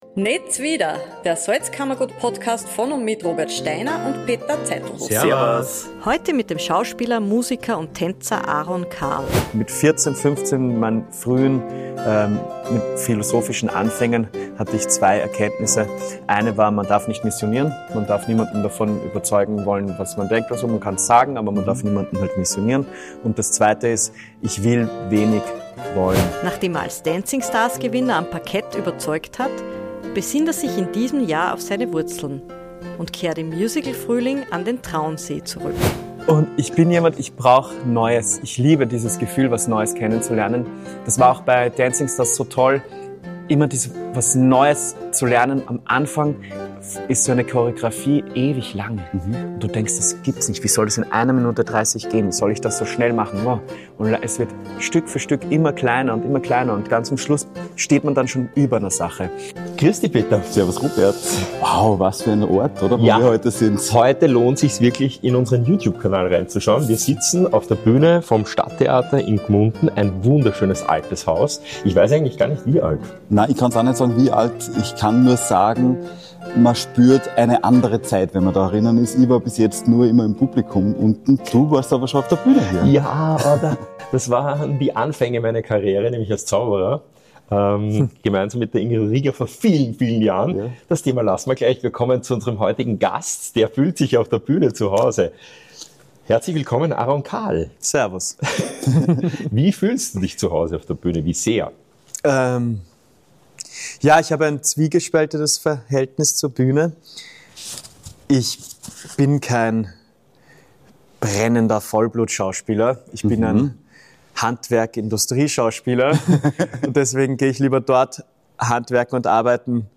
Aron Karl – Schauspieler, Tänzer, Fallschirmspringer und vielleicht bald Bundespräsident – sitzt mit uns auf der Bühne des Stadttheaters Gmunden.